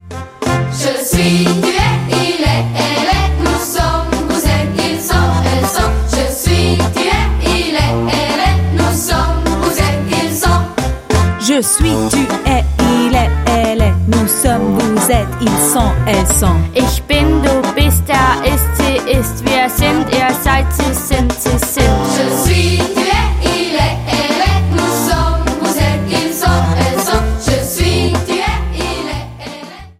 Lernlieder